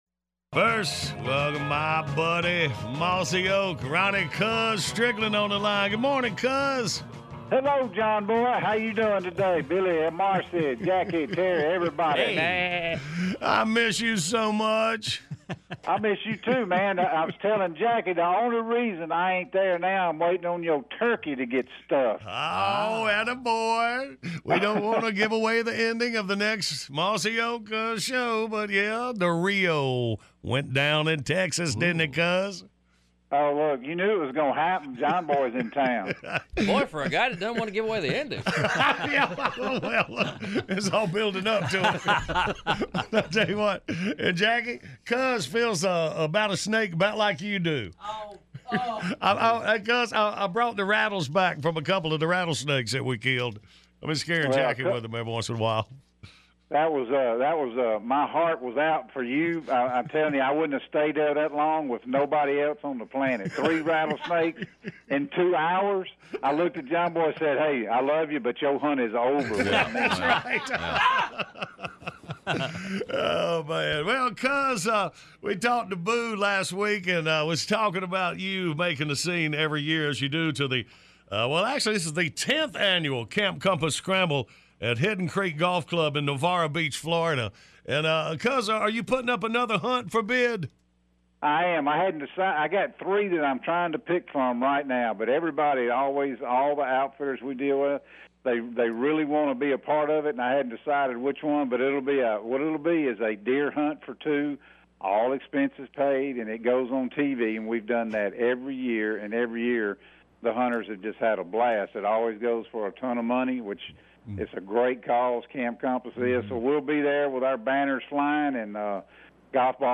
This interview was host by John Boy on the world famous John Boy and Billy show.